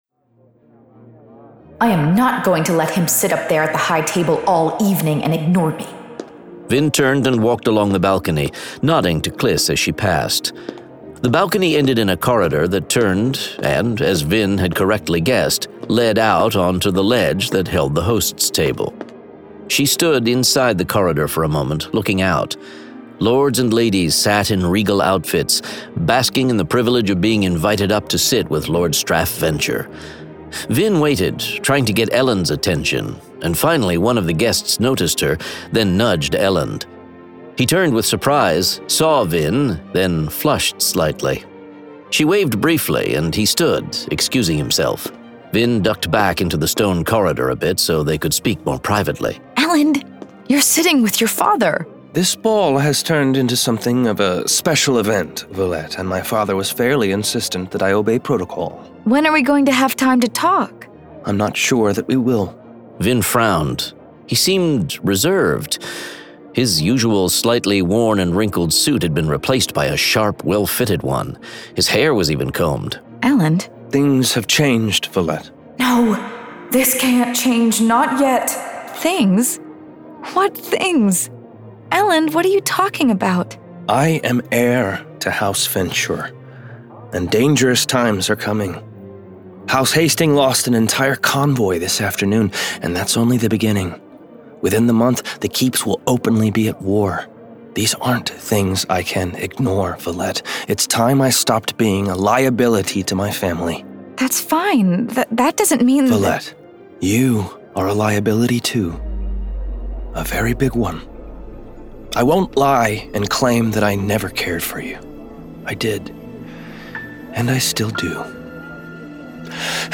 Full Cast. Cinematic Music. Sound Effects.
Genre: Fantasy